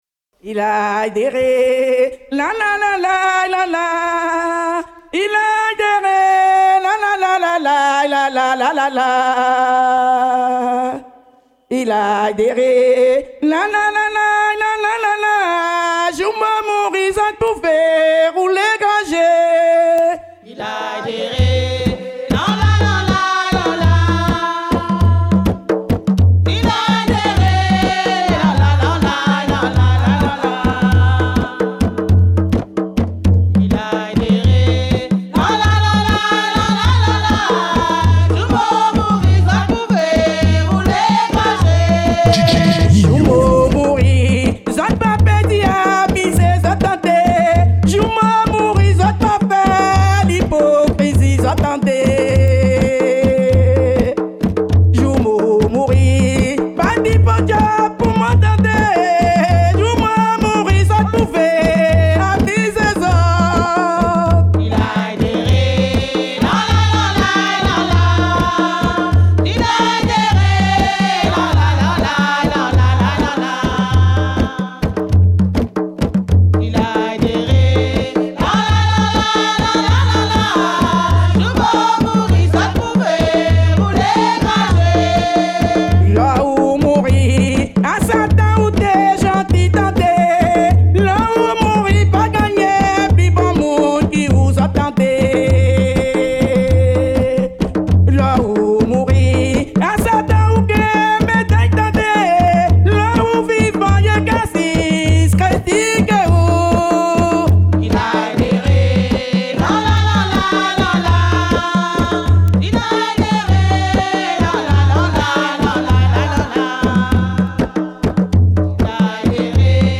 La musique traditionnelle de Guyane
Quarante minutes de musique traditionnelle
Laisser le son du tambour et des chants vous emporter vers les ancêtres et le continent d'origine l'Afrique.